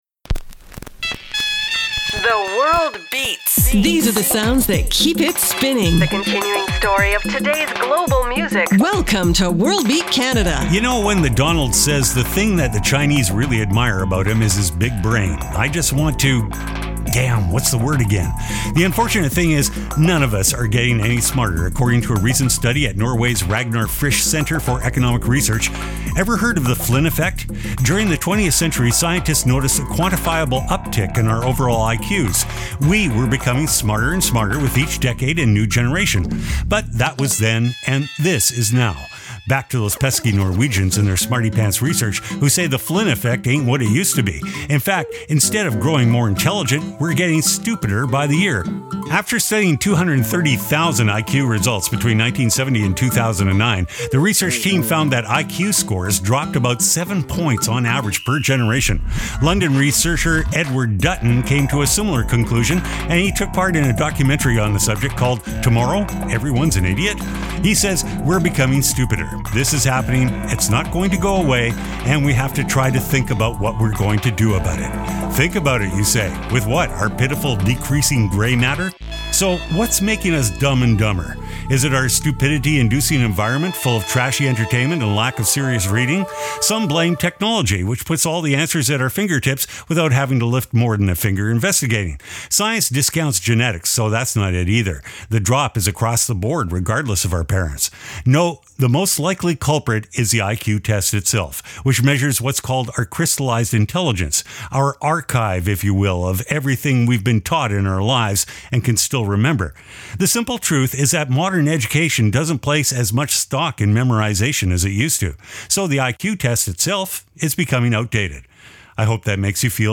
exciting contemporary global music alternative to jukebox radio